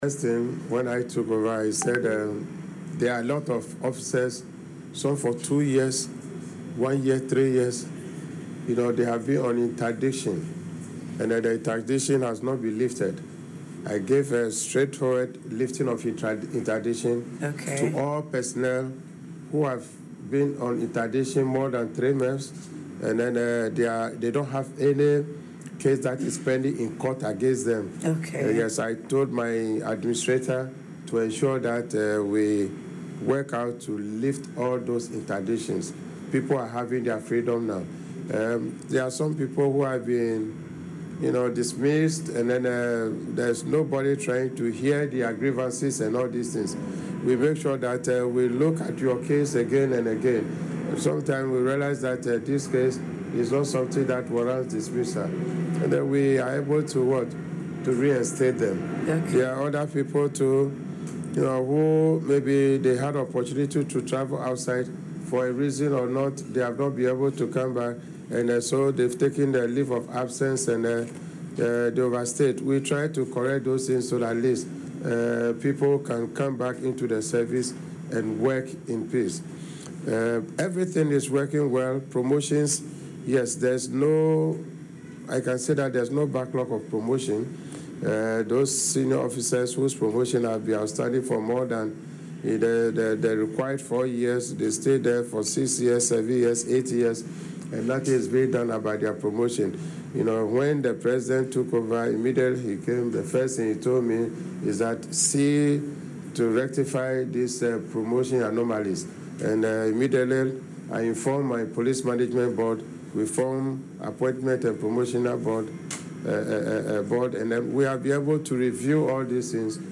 Speaking in an interview on Accra-based UTV, IGP Yohuno explained that upon assuming office, he inherited numerous unresolved matters affecting personnel welfare and career progression.